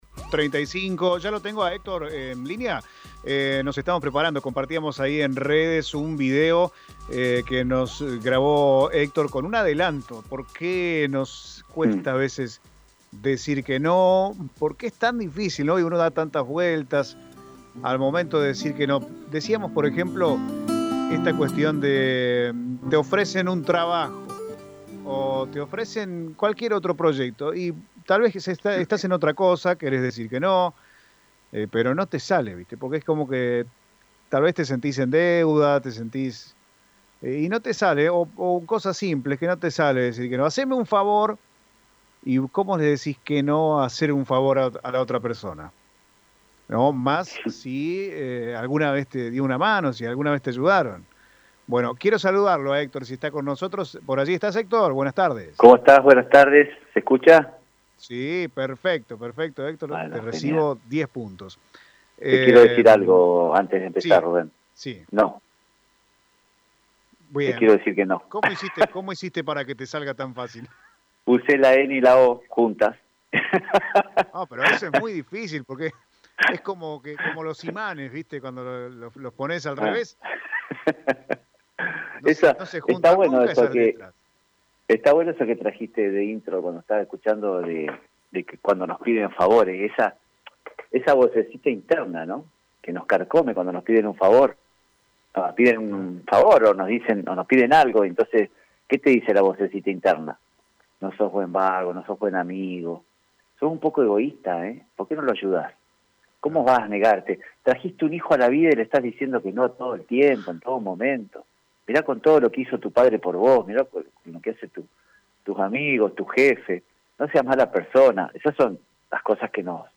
charla semanal con Radio EME